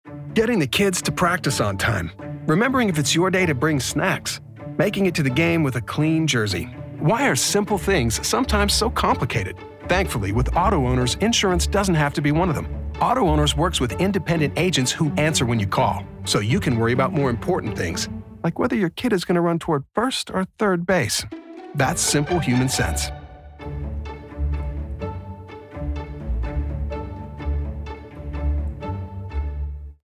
Agent Insurance Radio Ads